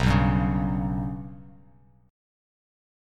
Bsus2#5 chord